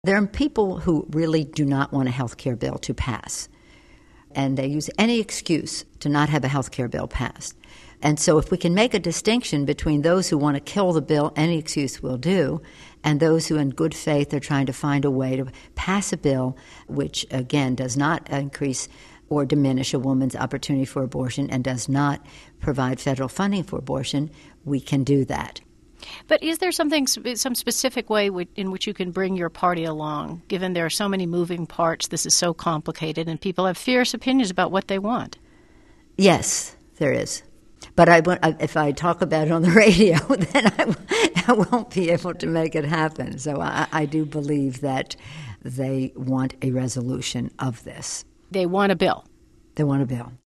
In an NPR interview, the House speaker predicted she can corral enough votes from moderate Democrats to guarantee final passage of health care legislation — even if it contains the less-restrictive rules on abortion contained in the Senate version of the bill.